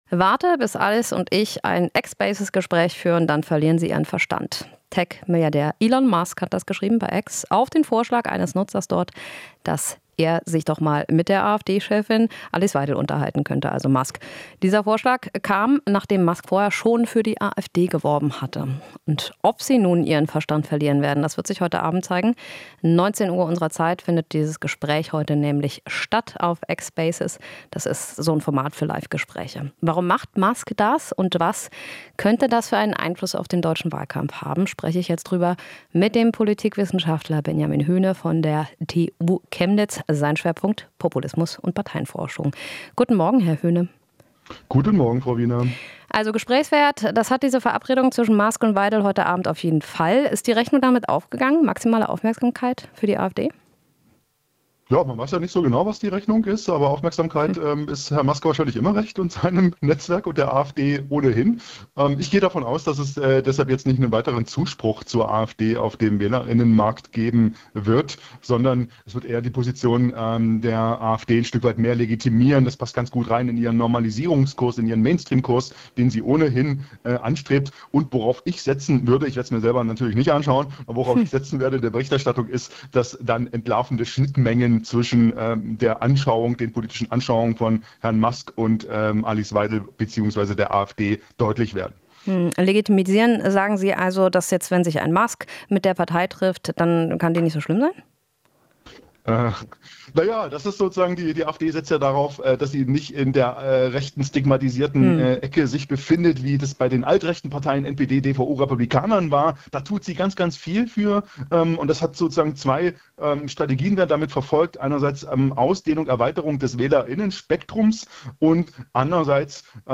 Interview - Experte: Musks Einmischung in den Wahlkampf problematisch